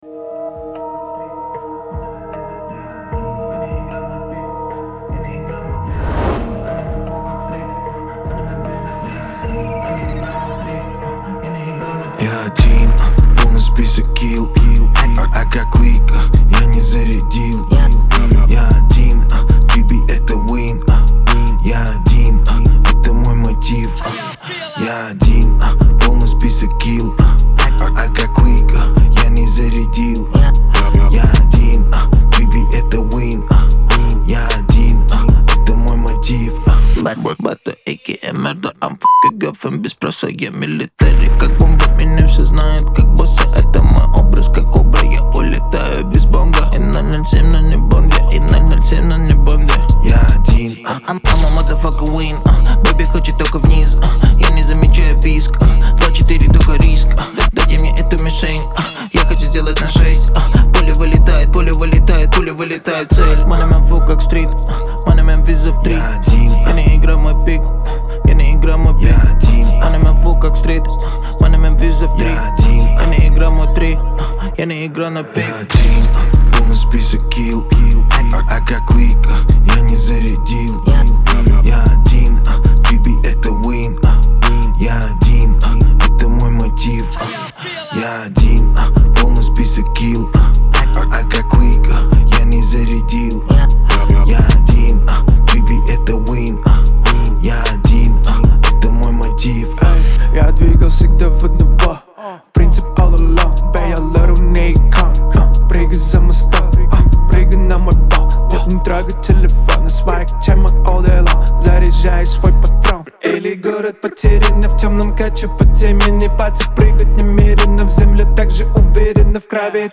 это яркий пример современного хип-хопа